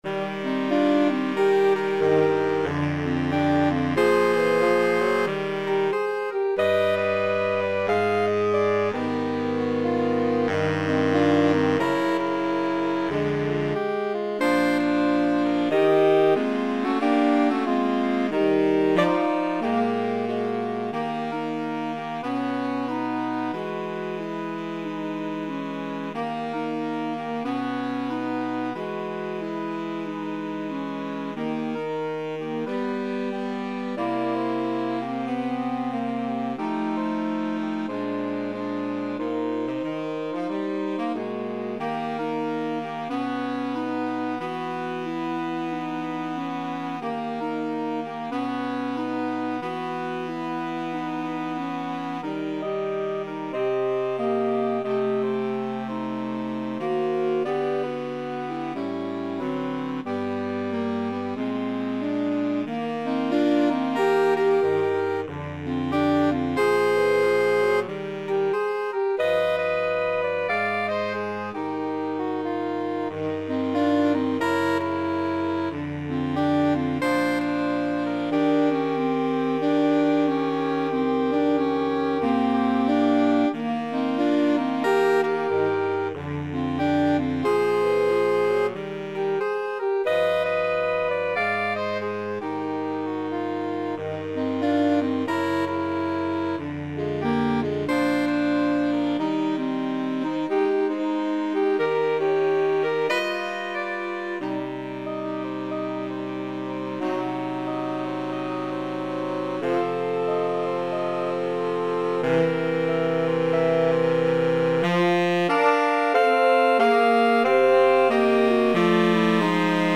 2/2 (View more 2/2 Music)
Moderato = c. 46
Jazz (View more Jazz Saxophone Quartet Music)